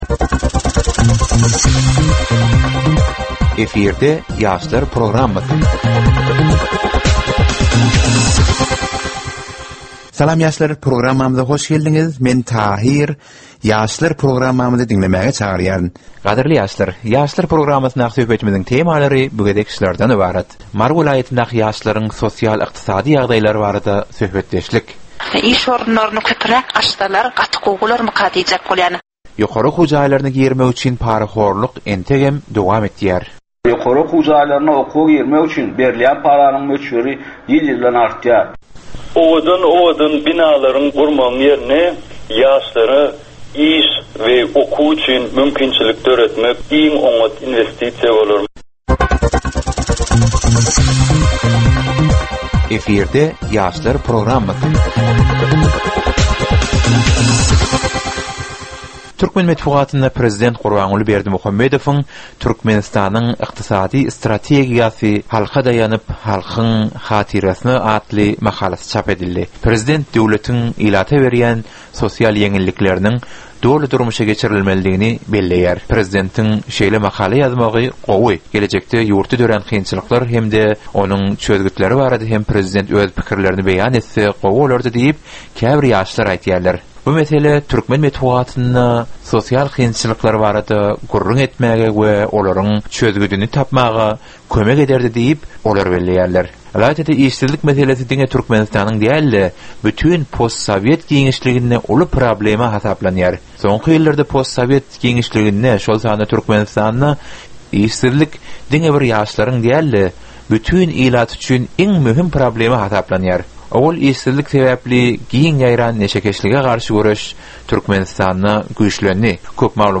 Türkmen we halkara yaşlarynyň durmuşyna degişli derwaýys meselelere we täzeliklere bagyşlanylyp taýýarlanylýan 15 minutlyk ýörite gepleşik.
Gepleşigiň dowamynda aýdym-sazlar hem eşitdirilýär.